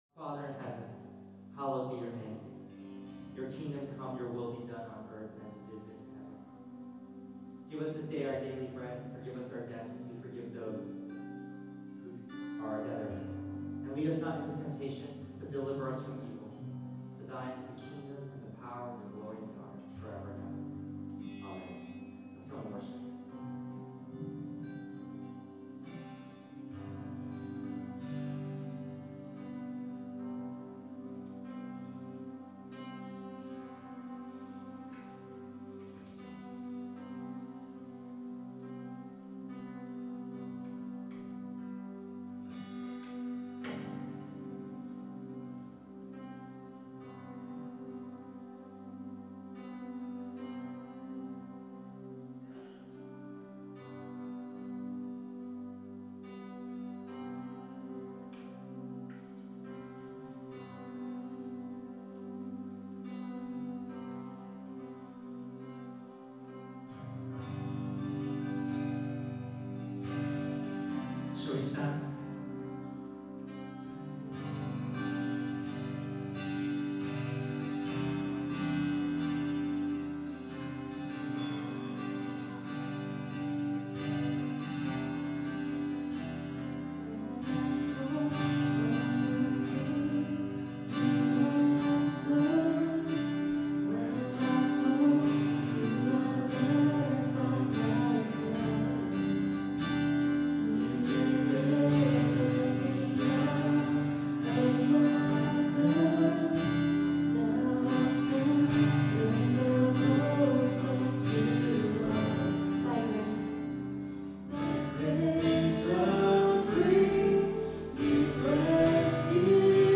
Worship May 17, 2015 – Birmingham Chinese Evangelical Church